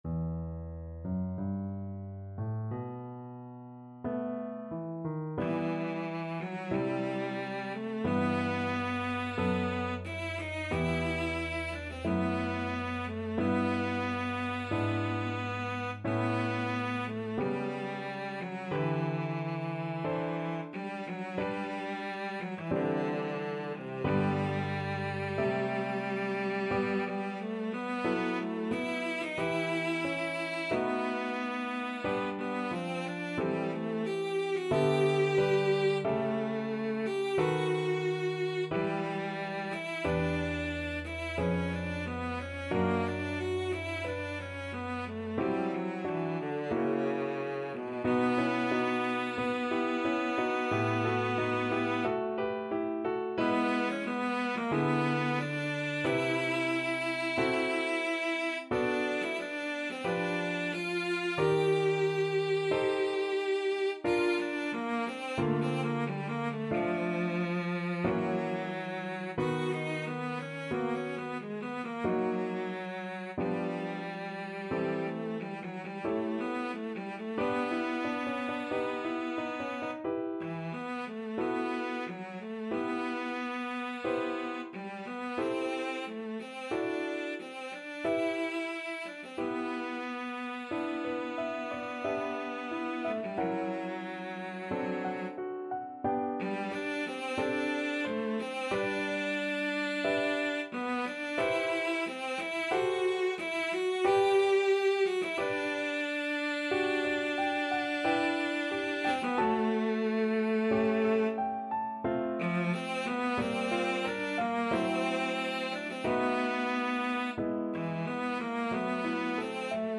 Adagio, molto tranquillo (=60) =45
Classical (View more Classical Cello Music)